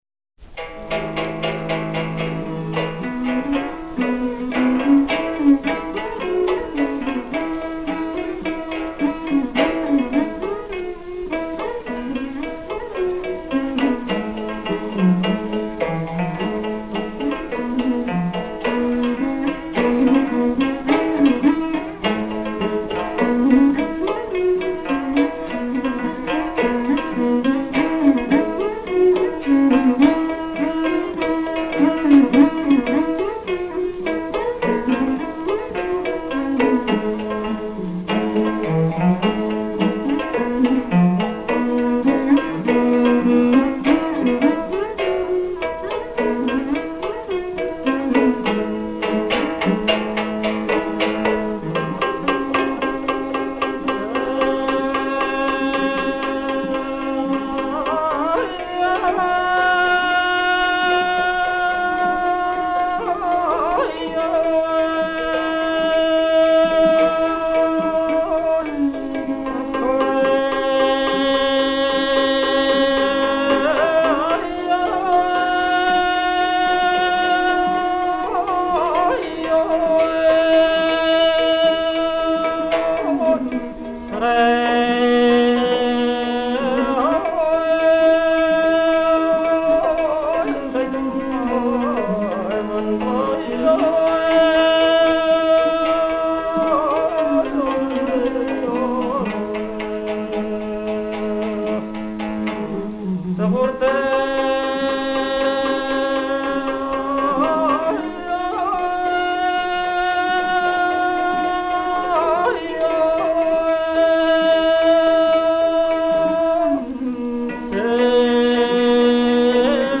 Genre: Early Ethnic